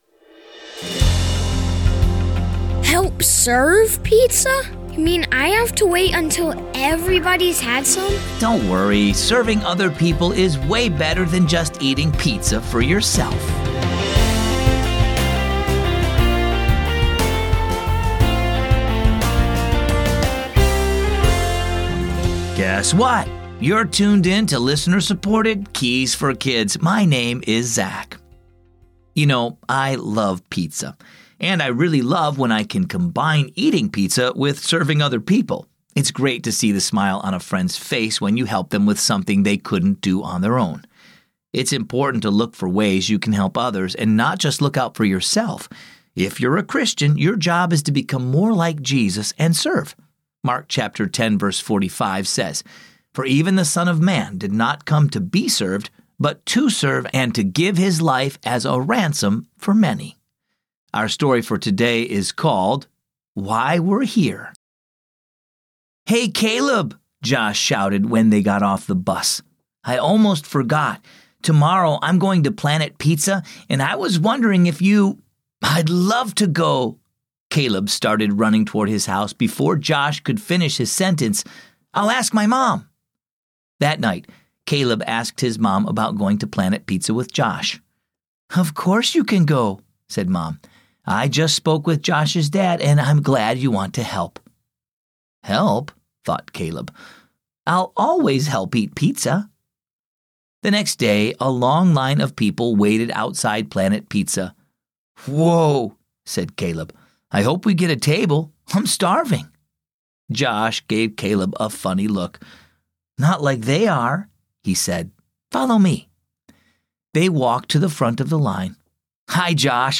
Keys for Kids is a daily storytelling show based on the daily Keys for Kids children's devotional.